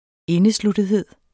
Udtale [ -sludəðˌheðˀ ]